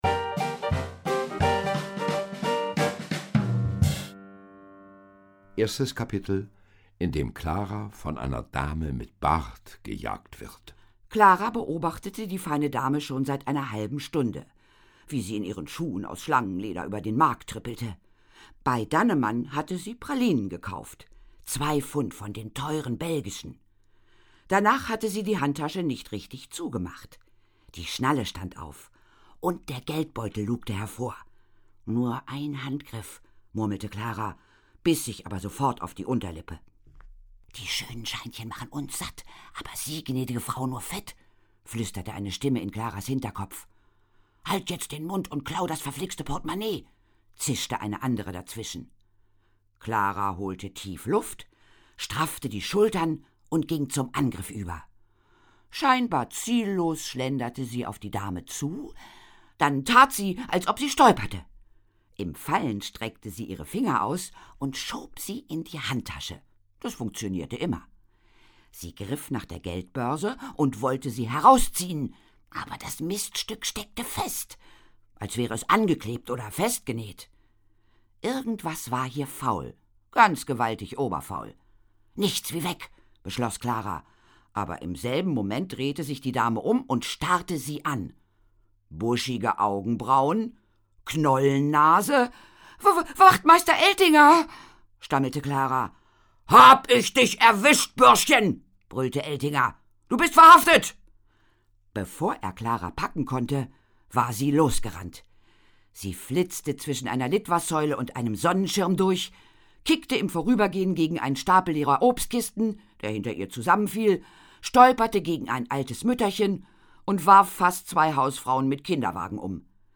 Hörbuch, 3 CDs, 236 Minuten